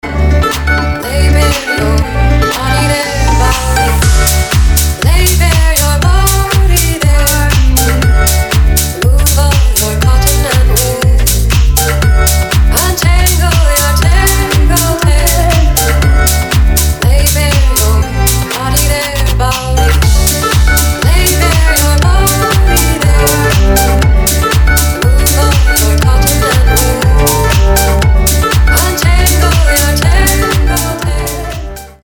• Качество: 320, Stereo
гитара
красивые
deep house
vocal